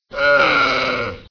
c_camel_hit2.wav